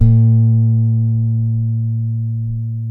SAMBA